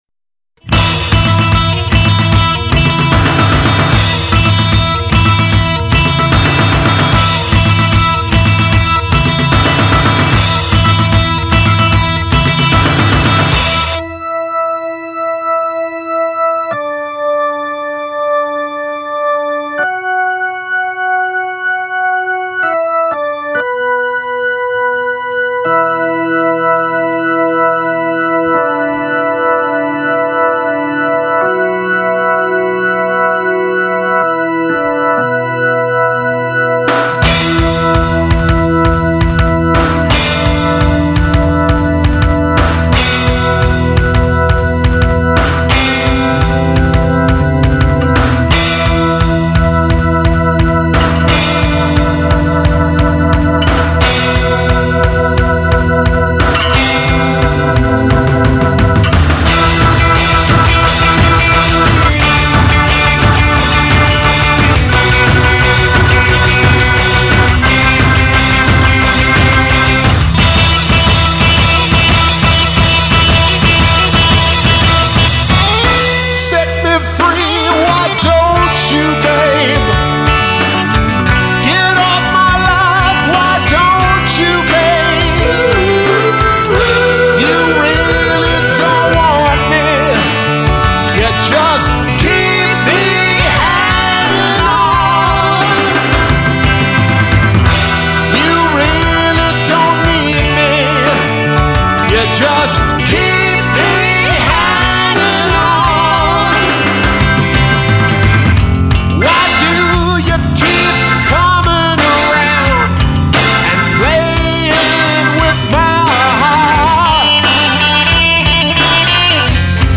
Classic Rock